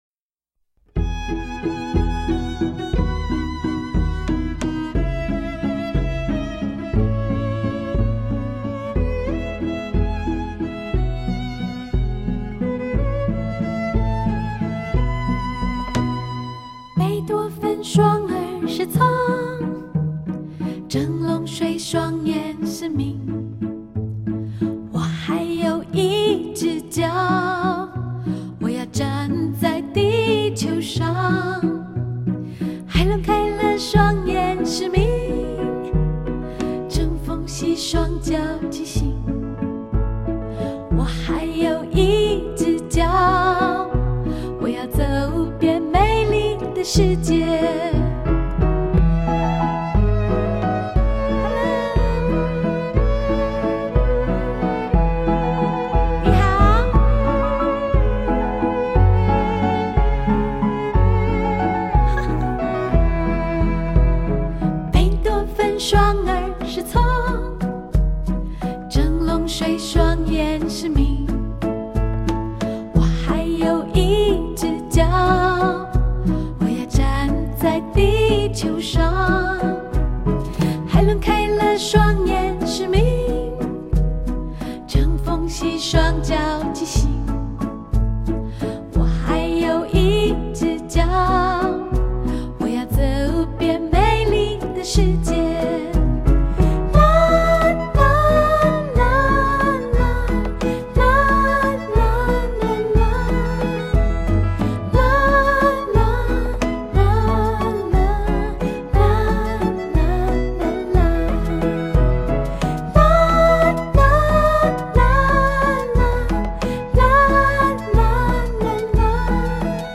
相信，這般清新悠揚的樂風，會為這充滿紛亂擾攘的世界，帶來ㄧ股清流般的心靈洗滌……